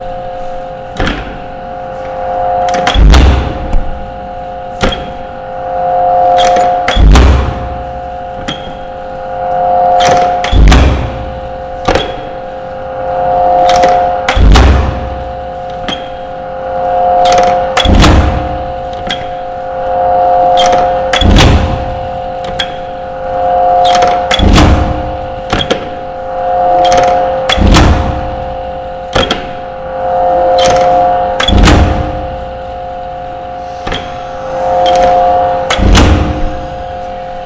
重型钥匙掉落在地毯上多
描述：重金属钥匙落在地板上
Tag: 重键 键 - 被滴下的 金属 金属键